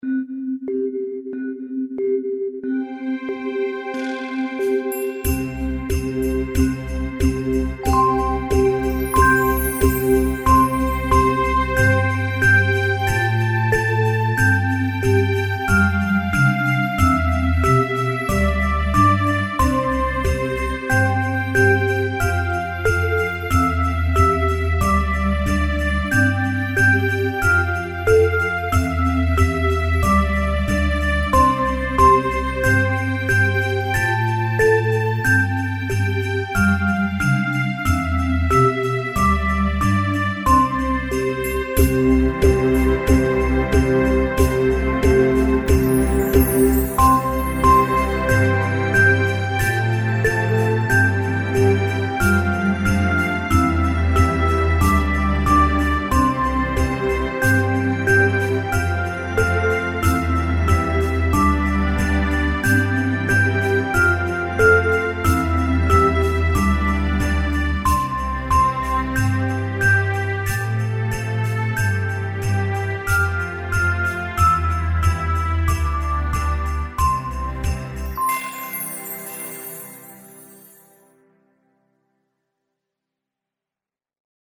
Christmas Karaoke
Christmas song, U.K.
(instrumental, 1:24 – 4/4 – 94 bpm)
Christmas Karaoke Song (Instrumental) YouTube License